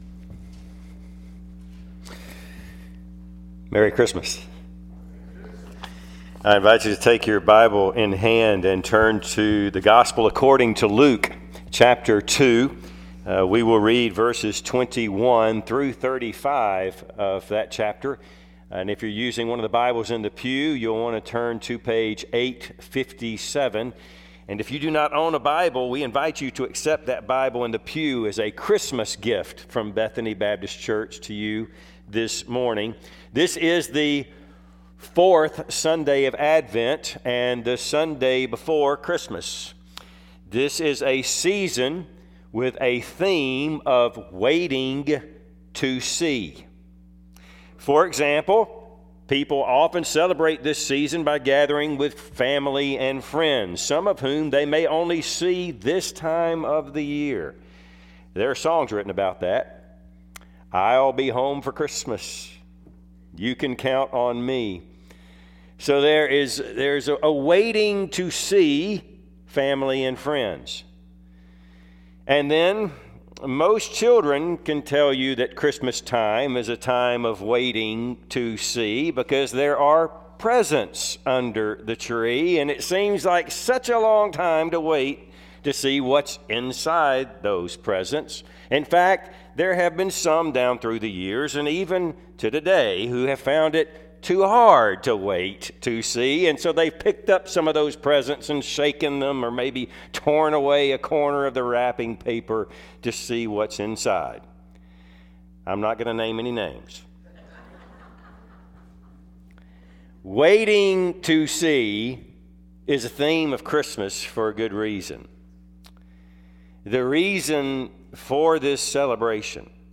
Passage: Luke 2:21-35 Service Type: Sunday AM